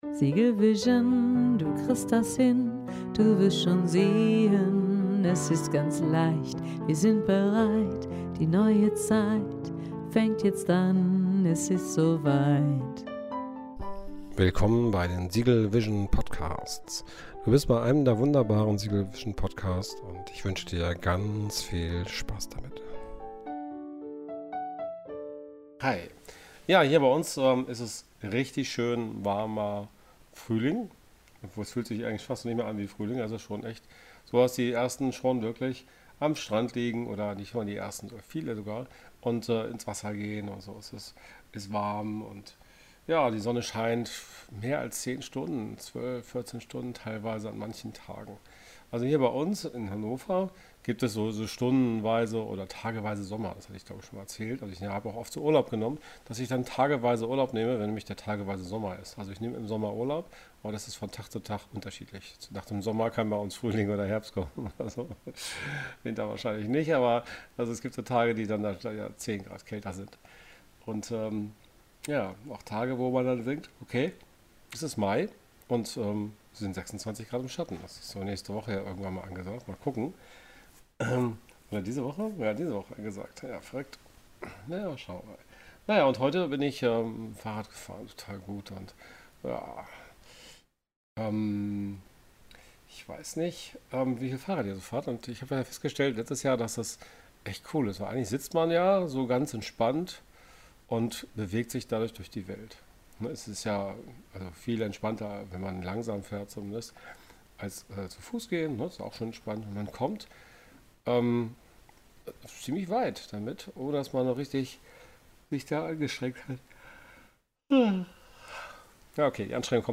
Wie wichtig sind Albernheiten in der heutigen schnelllebigen Zeit? Woher kommen Passagiere, die an der Milchstraße aussteigen ;-) Folge mit atmosphärischen Störungen ;-))